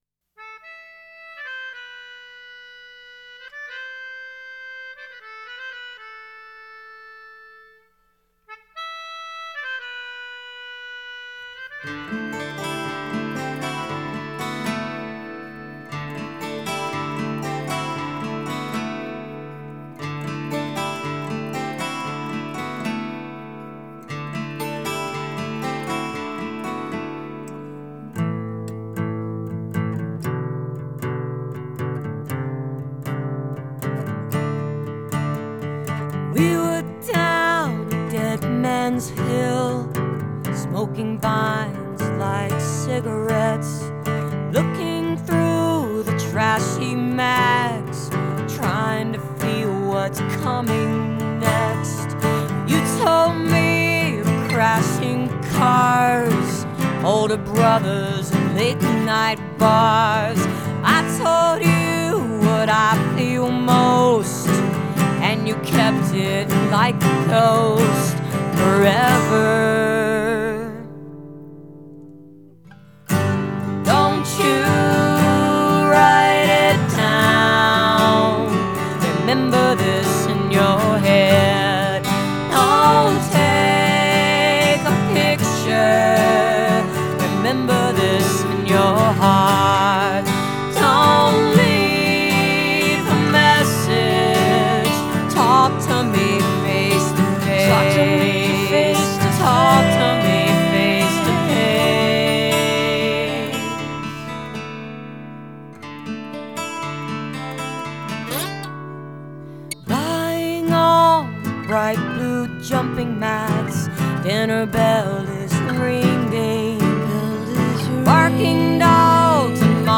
acoustic duo mix